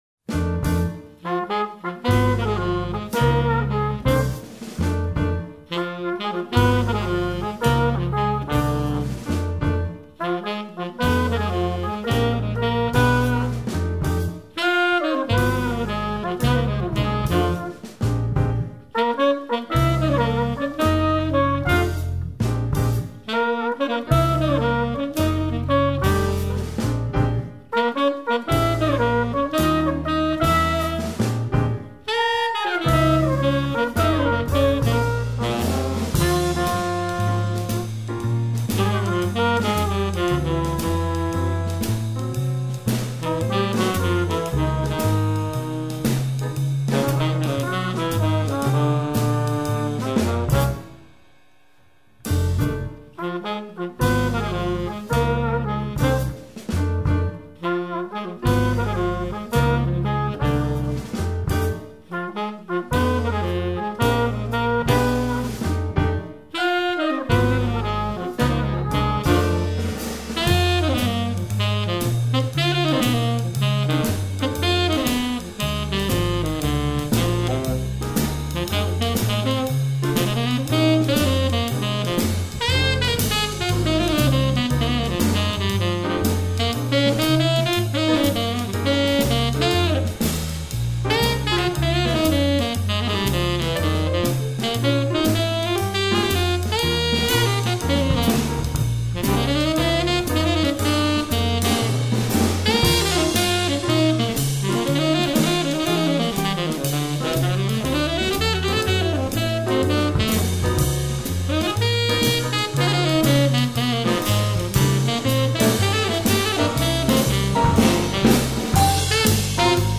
tromba, composizione, arrangiamento, direzione